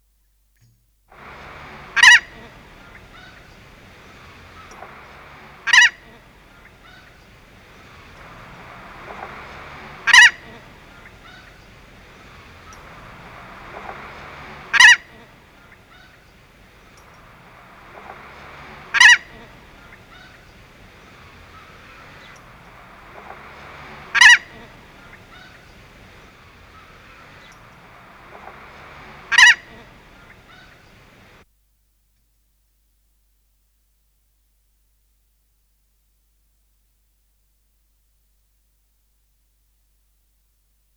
황제 기러기의 소리
흰머리기러기의 소리는 에드워드 윌리엄 넬슨에 따르면 "클라-하, 클라-하, 클라-하"처럼 들리며, 다른 기러기보다 "비음"이 더 강하게 들리는 것으로 구별할 수 있다.